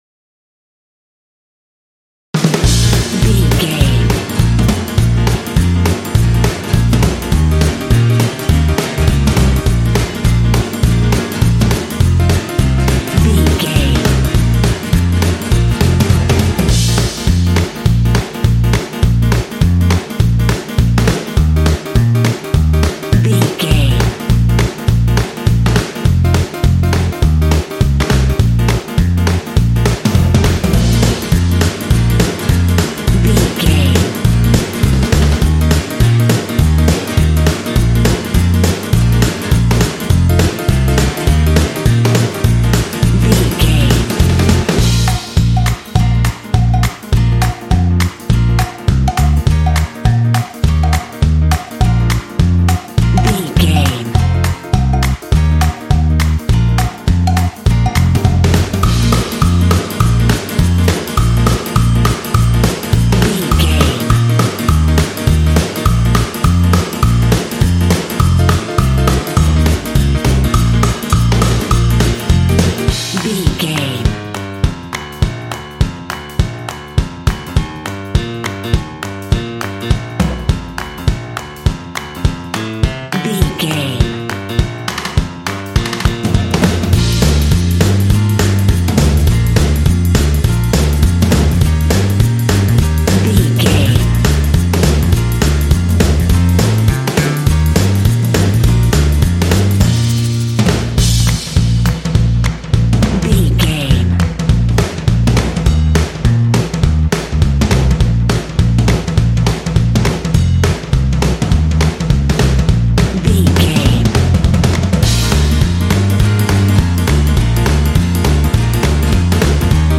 Ionian/Major
B♭
happy
energetic
groovy
lively
piano
bass guitar
drums
horns
brass
jazz